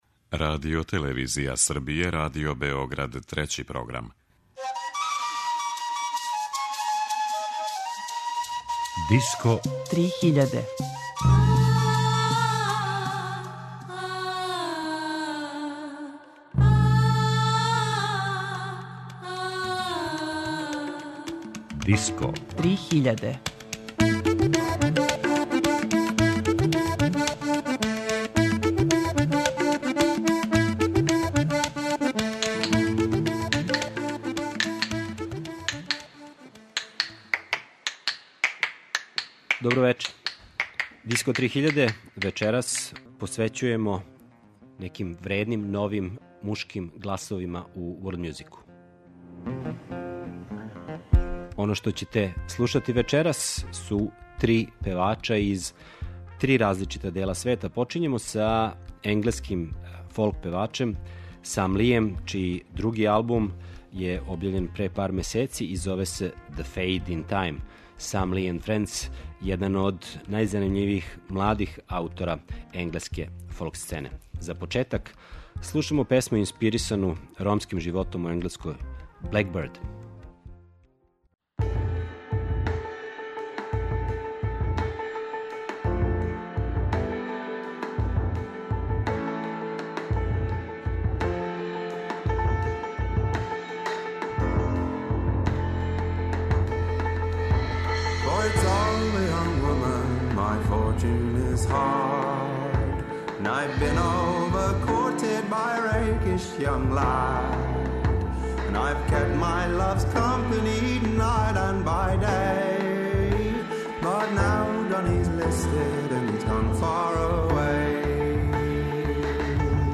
Мушки гласови у светској музици данас